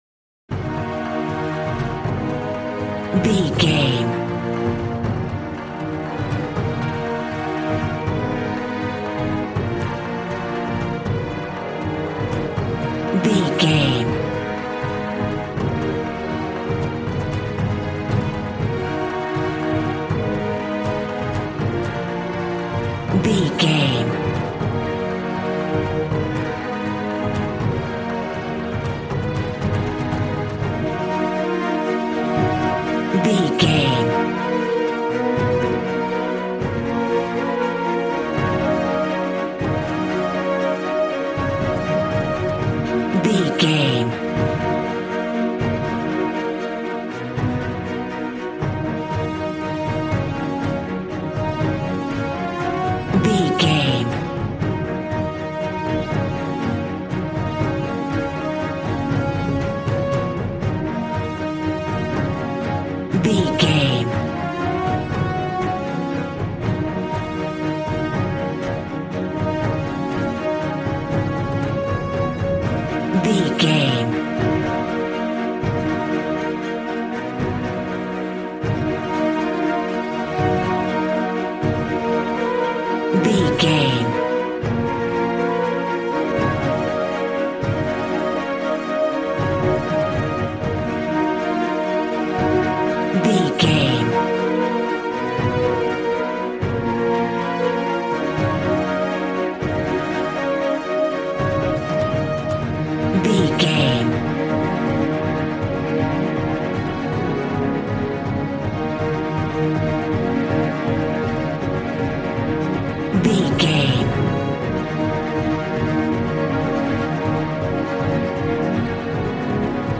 Aeolian/Minor
E♭
dark
piano
synthesiser